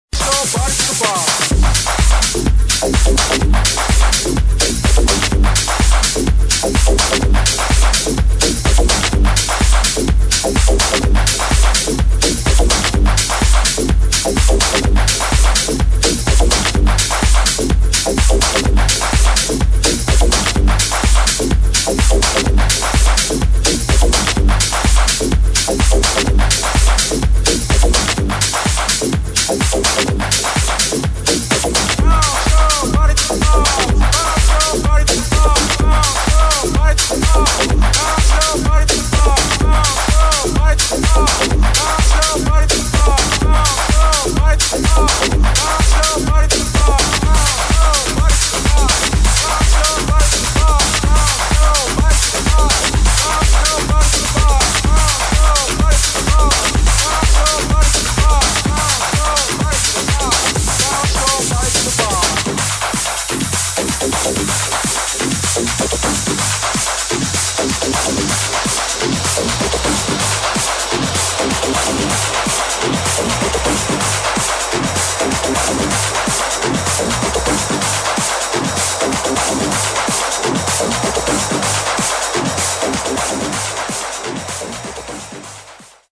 [ TECHNO / HOUSE ]